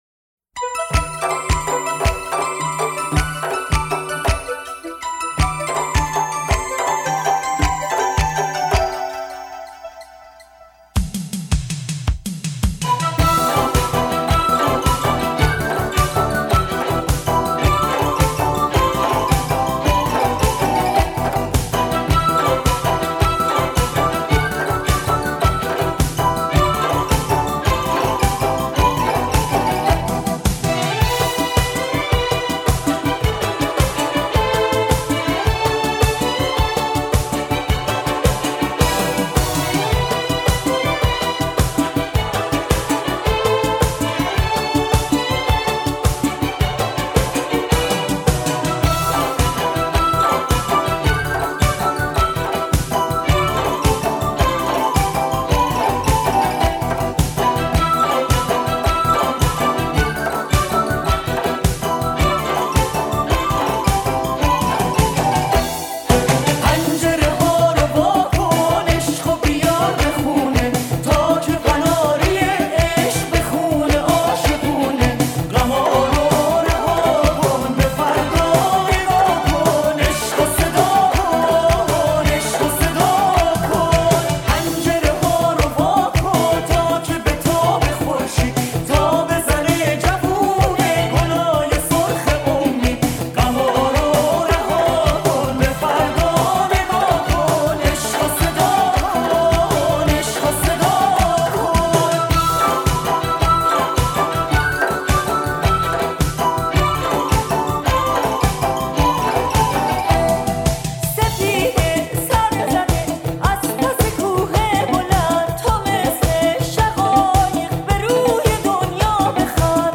عزیزانی که با صدای زن مشکل شنوایی دارن، گوش ندن.